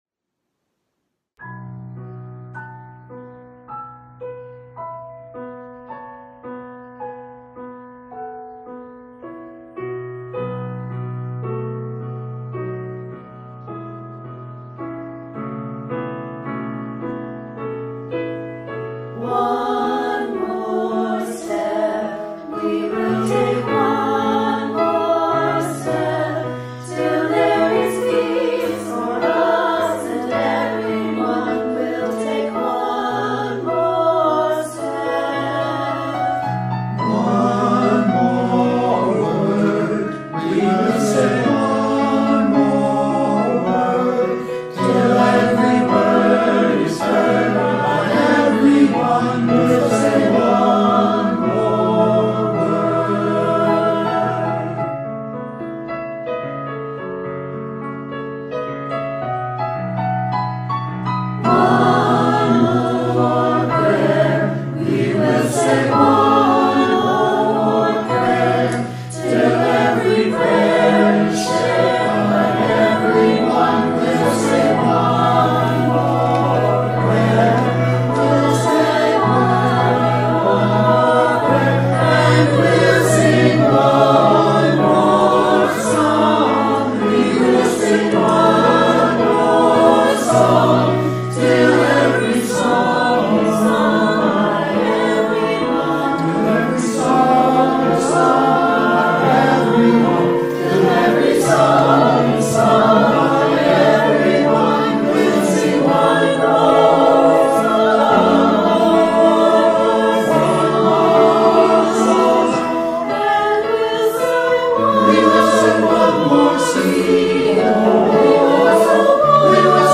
A richly imagined arrangement of a visionary protest anthem.
SATB, piano